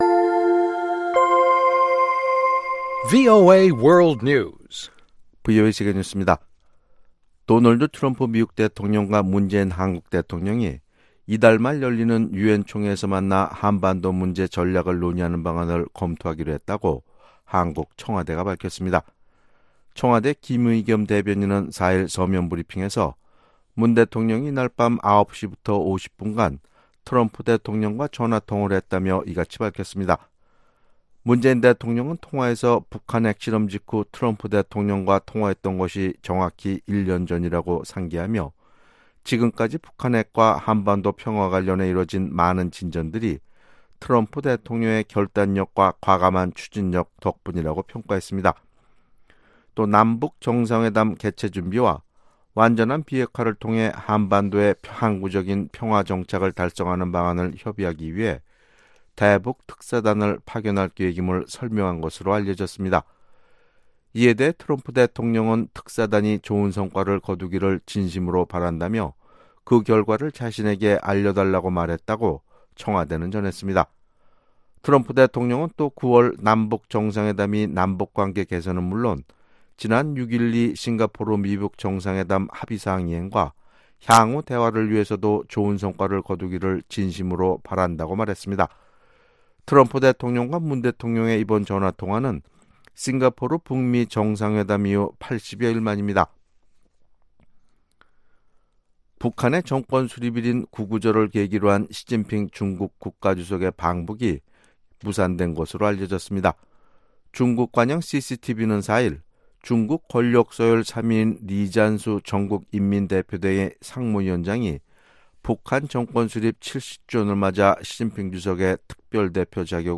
VOA 한국어 아침 뉴스 프로그램 '워싱턴 뉴스 광장' 2018년 9월 5일 방송입니다. 한국 청와대가 다음달 5일 평양을 방문하는 특사단이 교착 상태에 빠진 미-북 간 비핵화 협상의 마중물이 되기를 바란다고 밝혔습니다. 대북 압박 캠페인을 주도해 온 미 상원의원들이 추가 제재 부과가능성을 거듭 시사했습니다. 사전 준비가 전혀 없이 시작된 미-북 정상회담은 처음부터 실패가 예정돼 있었다고 리언 파네타 전 미국 국방장관이 지적했습니다.